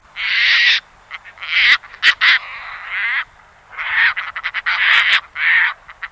Sterne caspienne, sterna caspia
Sterne caspienne